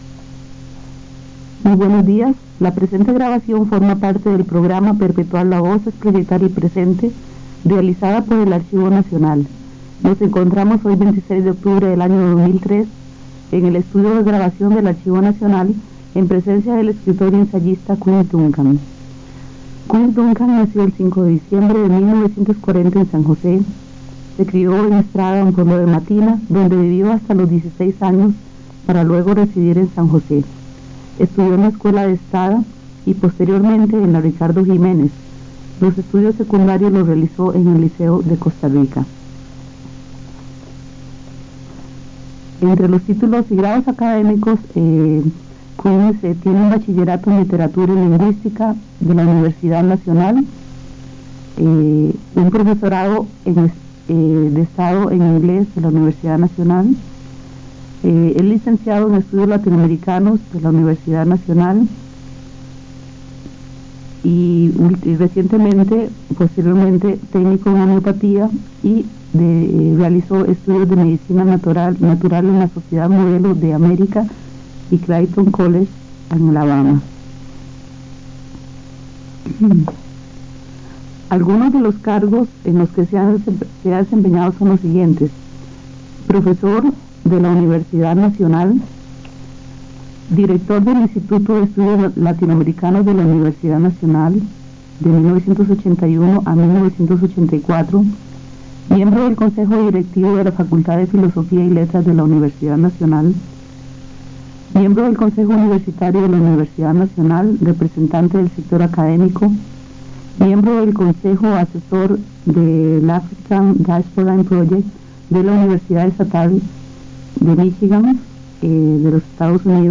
Grabación de la entrevista a Quince Duncan, escritor - Archivo Nacional de Costa Rica
Notas: Casete de audio y digital